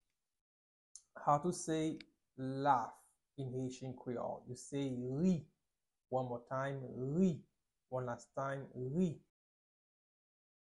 Pronunciation:
18.How-to-say-Laugh-in-haitian-creole-–-Ri-pronunciation.mp3